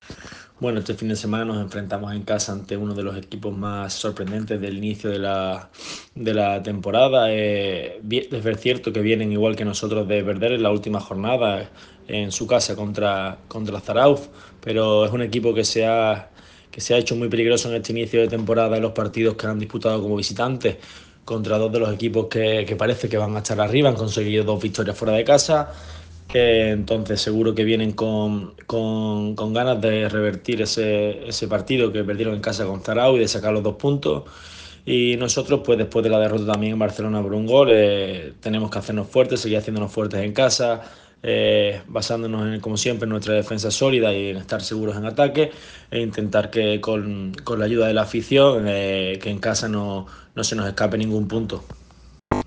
Manifestaciones del jugador del Trops Málaga